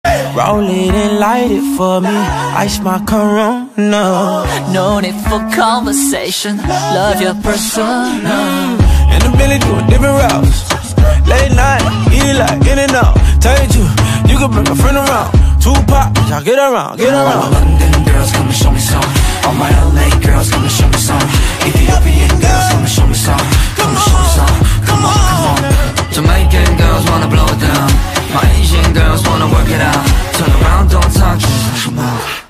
Kategori POP